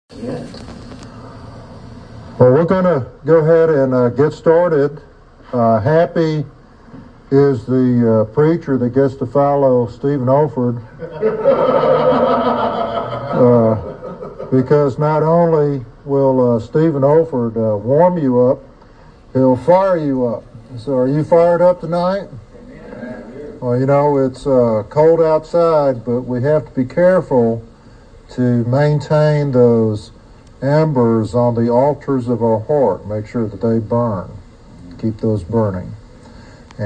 In this classic devotional sermon